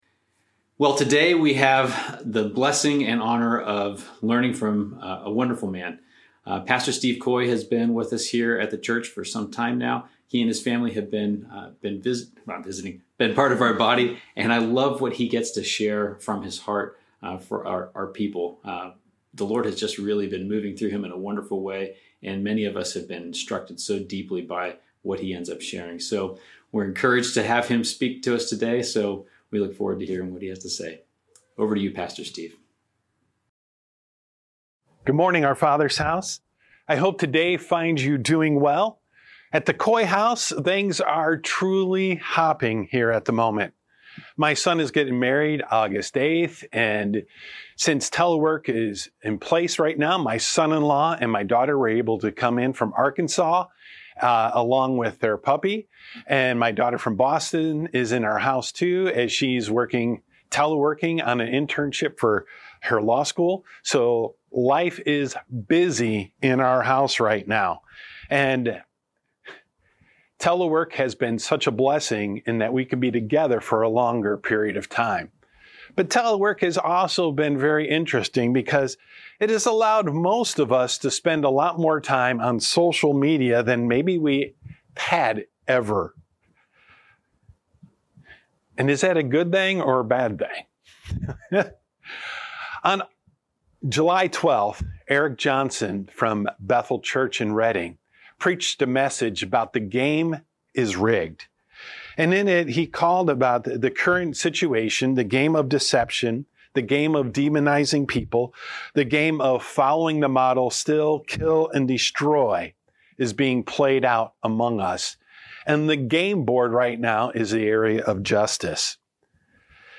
00:00 Start05:34 Introduction08:38 Worship Introduction13:42 Worship39:45 Announcements43:33 Sermon1:20:12 Communion1:24:25 Closing announcements